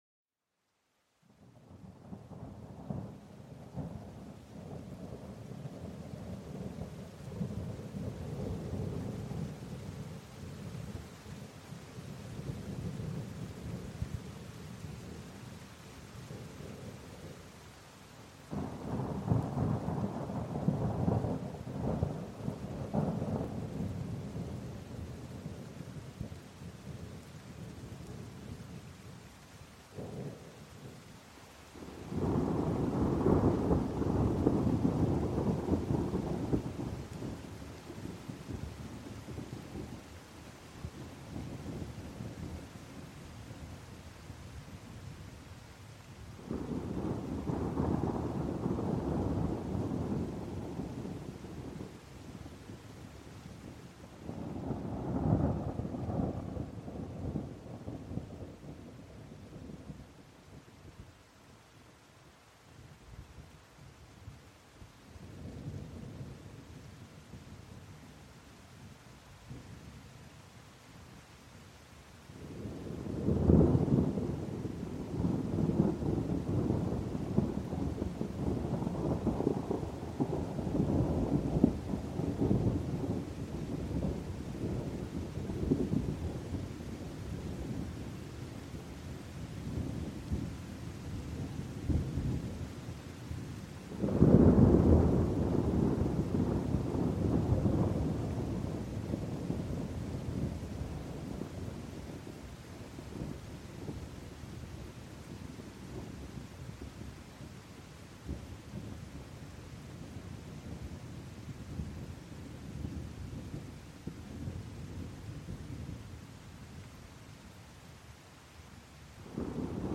Sonidos de Tormenta y Lluvia para una Relajación Profunda
Sumérgete en los sonidos relajantes de la tormenta y la lluvia. Escucha los truenos y las gotas de lluvia para una relajación total.